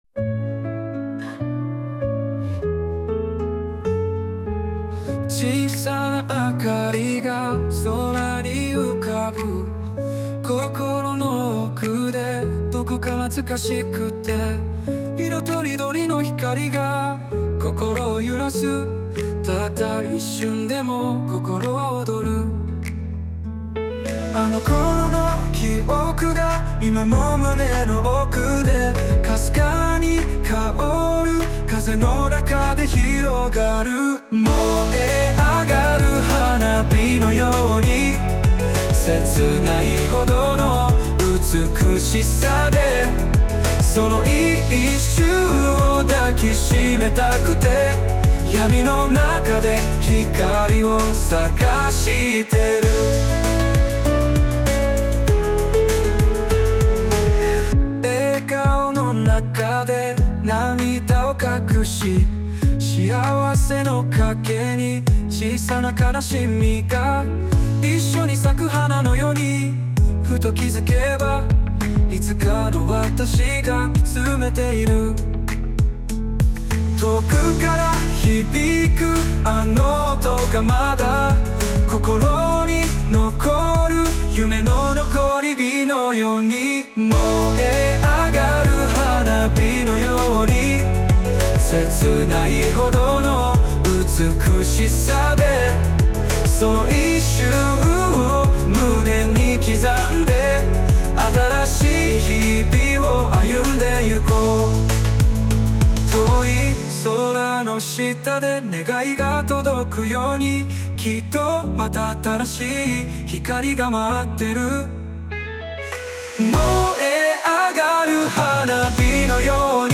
邦楽男性ボーカル著作権フリーBGM ボーカル
男性ボーカル（邦楽）曲です。